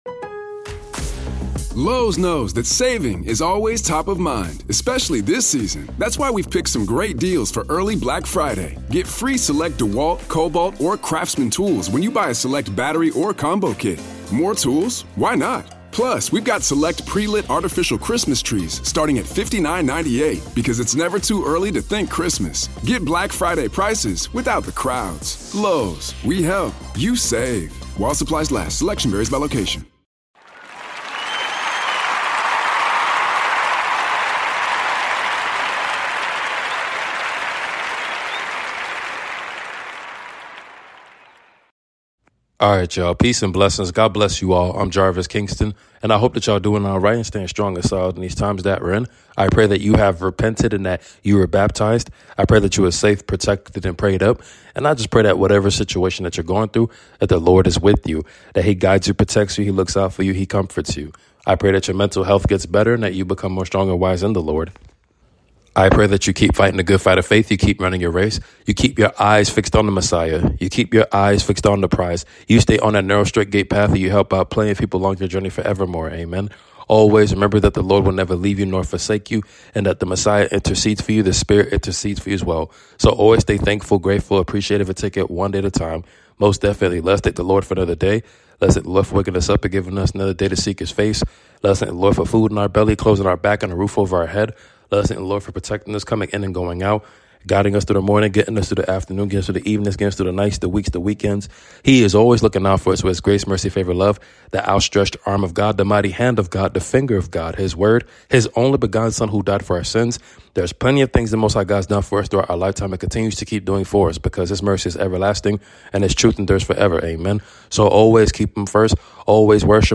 Your Nightly Prayer 🙏🏾 Psalm 128:2